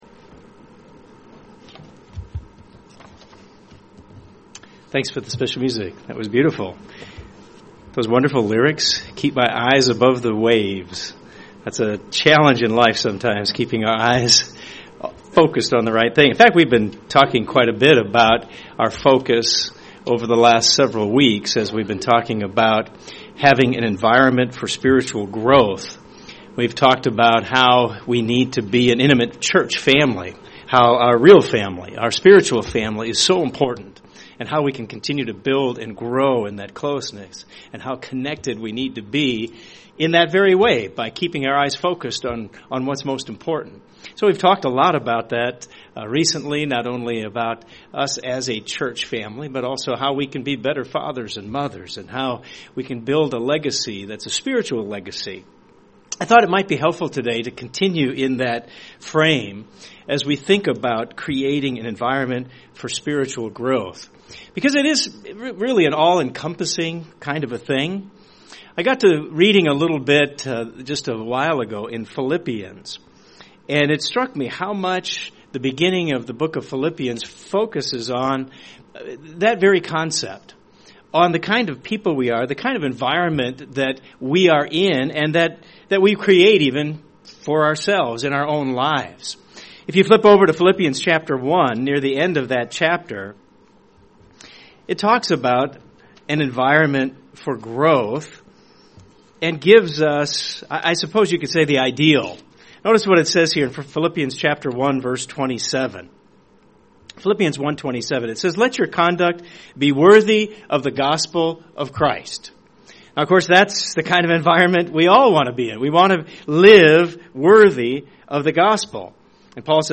This sermon examines three things (from Philippians 2:3-4) in creating an environment for spiritual growth in your life and for others in church.